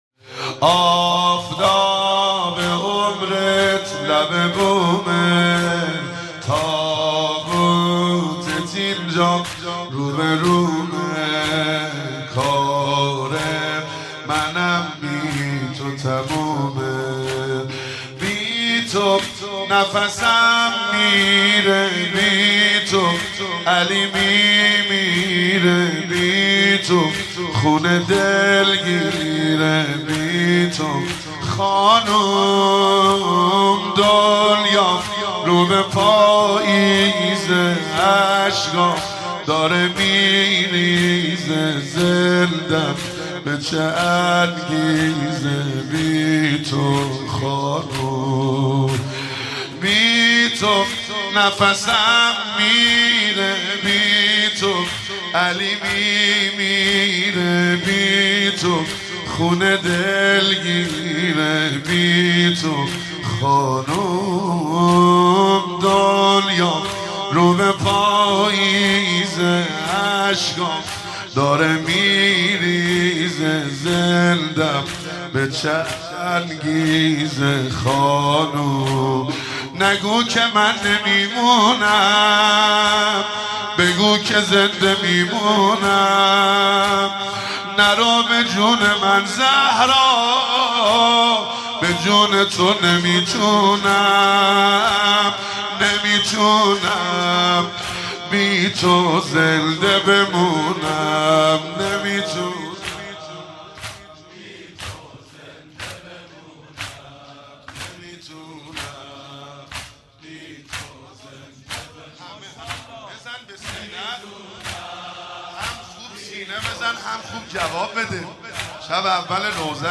مداحی و نوحه
دانلود مداحی فاطمیه
روضه خوانی، شهادت حضرت فاطمه زهرا(س